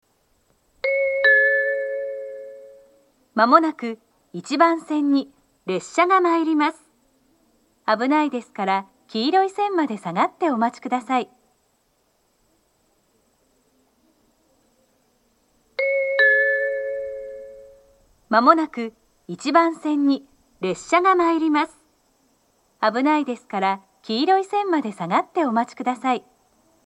１番線接近放送